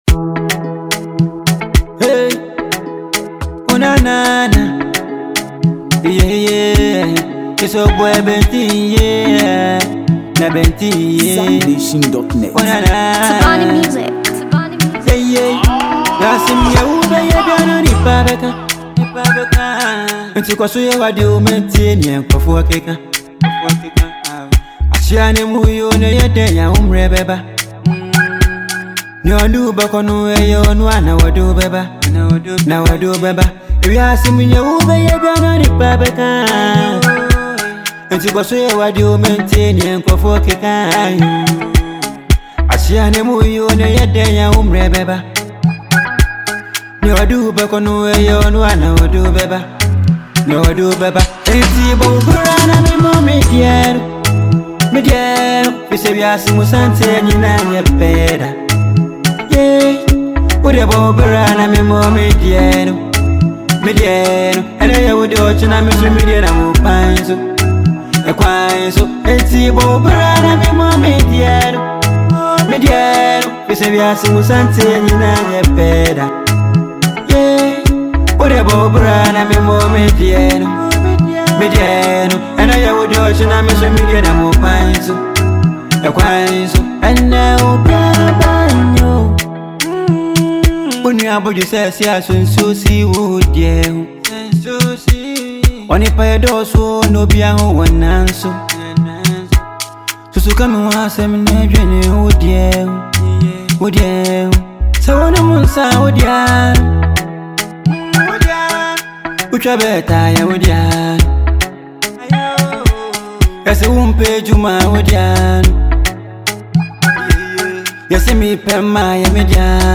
smooth vocals
feel-good music with a touch of emotional depth.